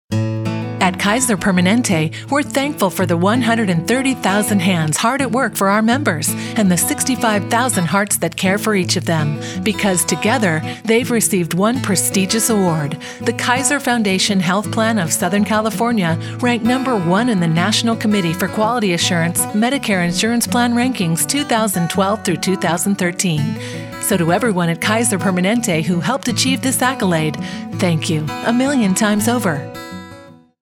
Commercial Authentic
My voiceover style is consistently described as authentic, calm, and trustworthy, offering a smooth, conversational and friendly delivery that truly connects.
Operating from a professional home studio utilizing Adobe Audition, I meticulously record, produce and deliver pristine, polished voiceover recordings with exceptional attention to detail.